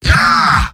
Giant Robot lines from MvM. This is an audio clip from the game Team Fortress 2 .